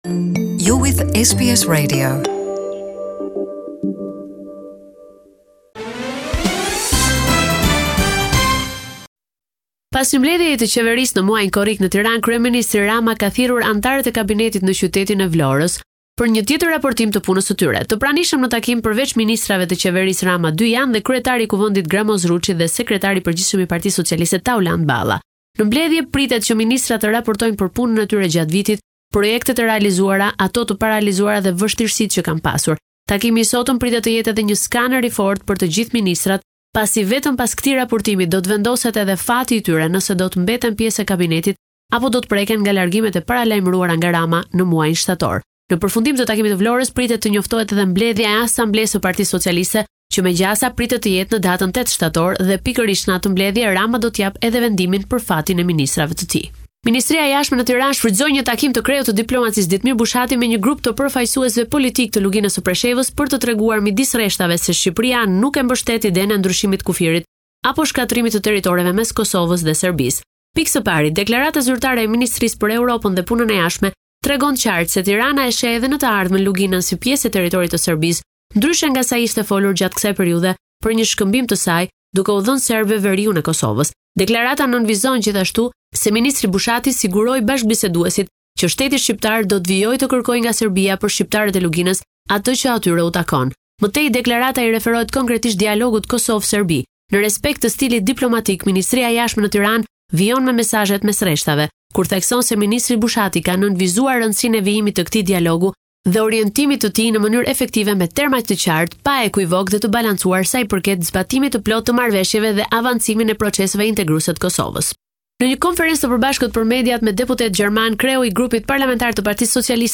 This is a report summarising the latest developments in news and current affairs in Albania